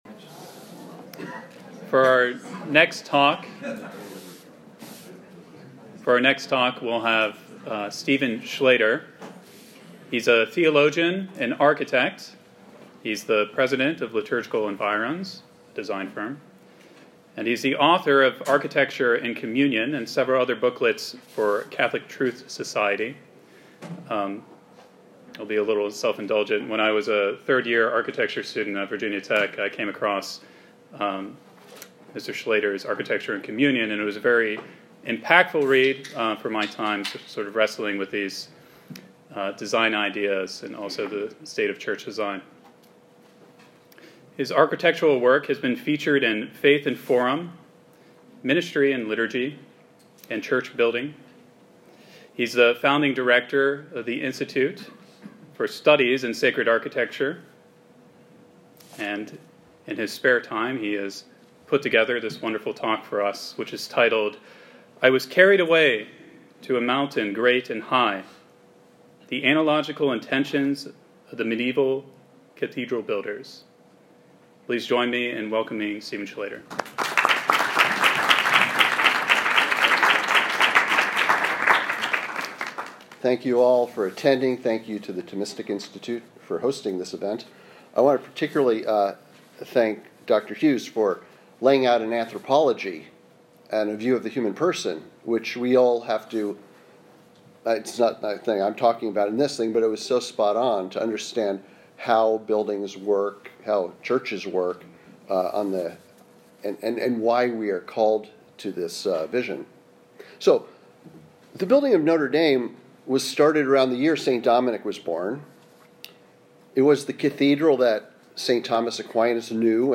This lecture was given at New York University on November 16, 2019.